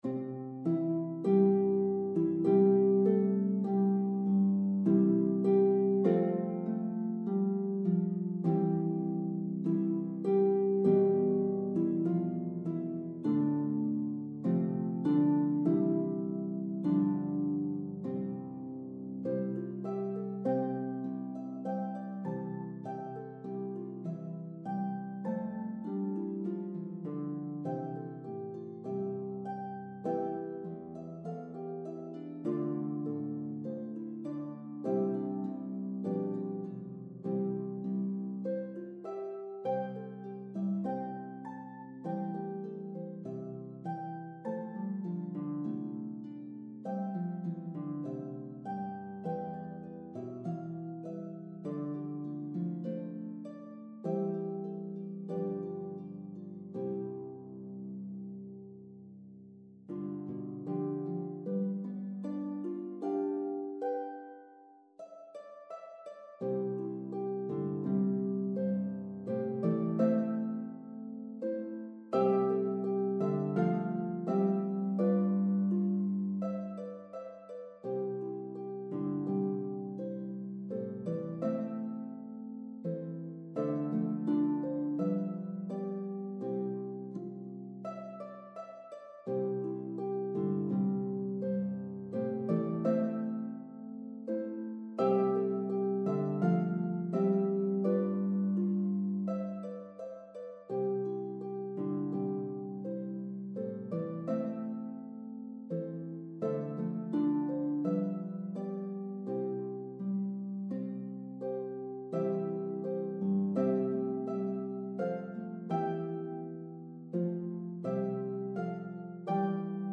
Afro-American Spirituals